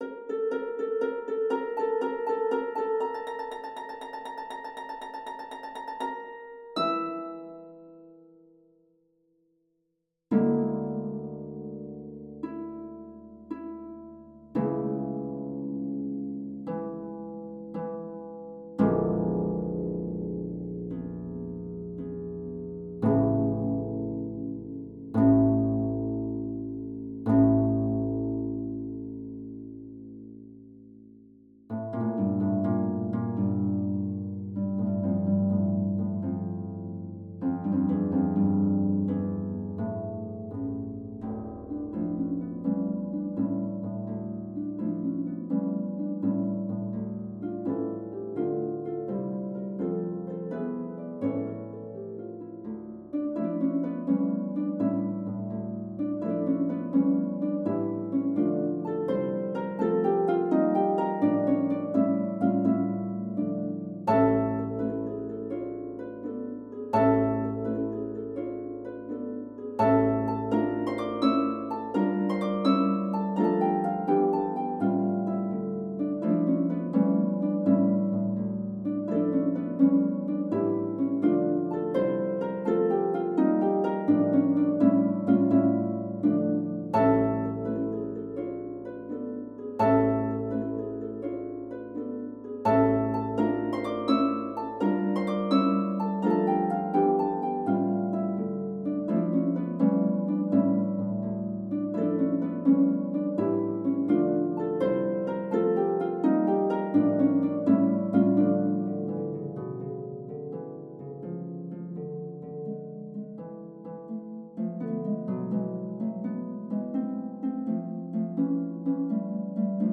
for two pedal harps